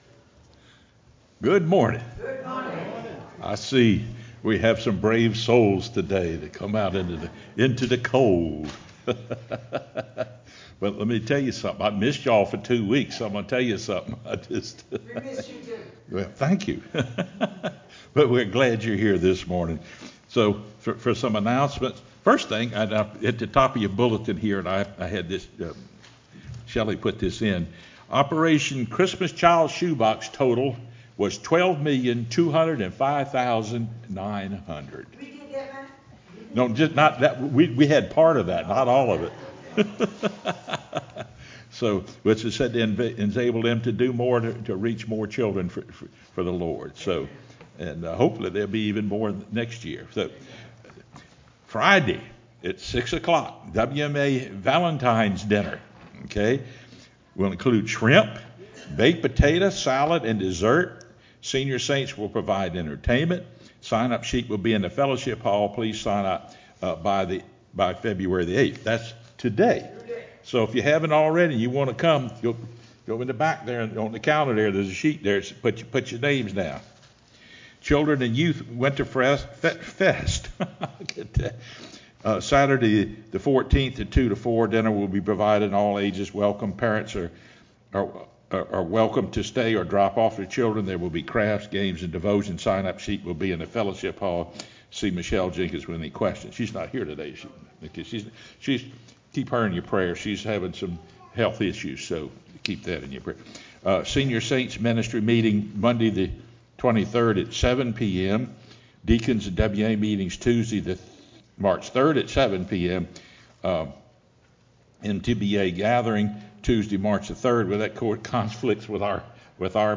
sermonFeb08-CD.mp3